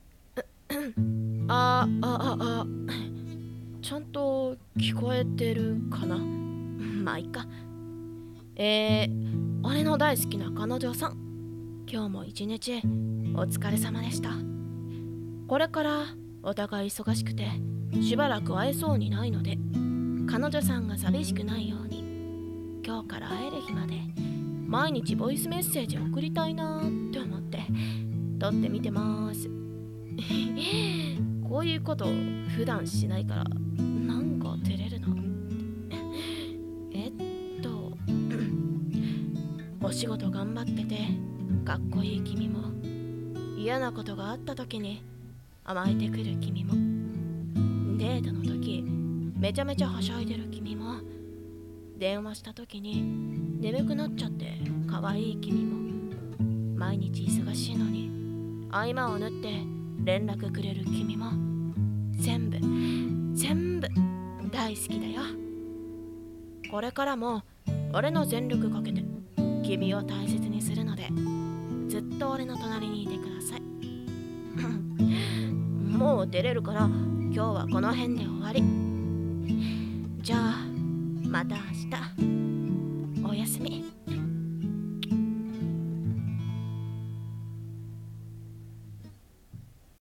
【声劇】ボイスメッセージ